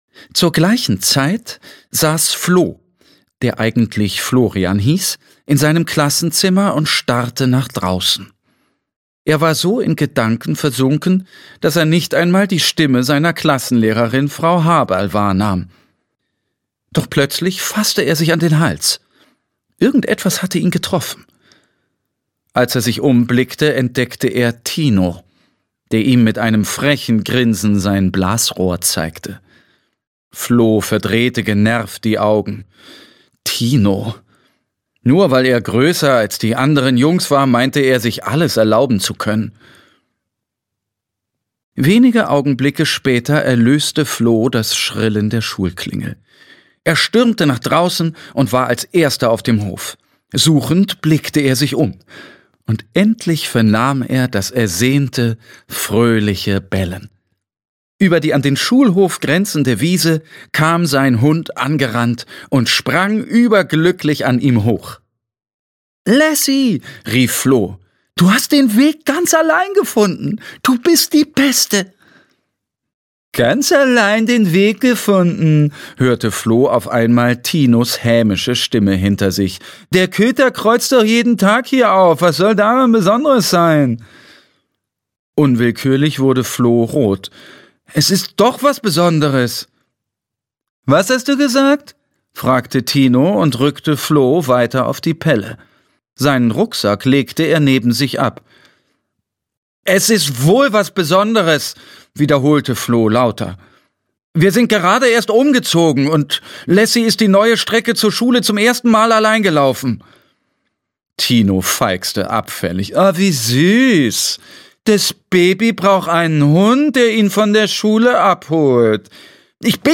Lassie - Eine abenteuerliche Reise - Mark Stichler - Hörbuch